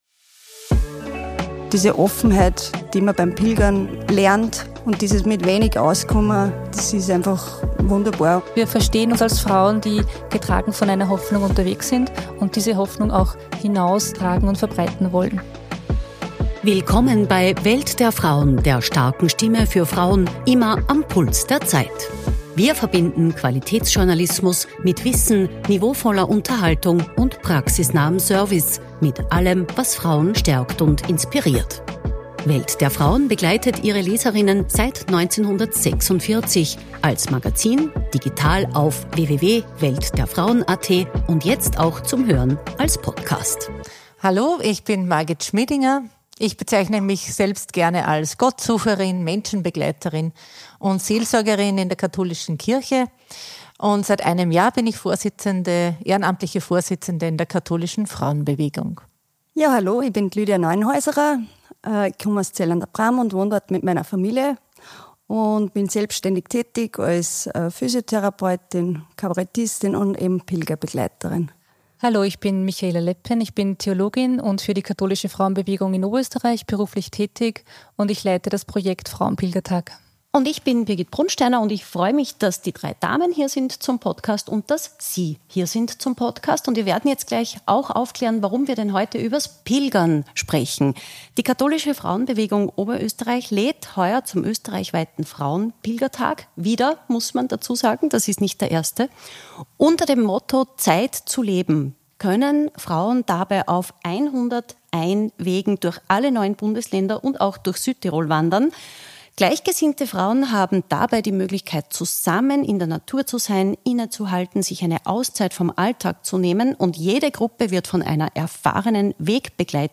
Was machen Profipilgerinnen, wenn sie doch einmal Blasen an den Füßen bekommen und warum pilgern sie am liebsten mit anderen Frauen? Ein Gespräch darüber, wie Gehen die Gedanken in Bewegung bringt, und was das mit der eigenen Person macht.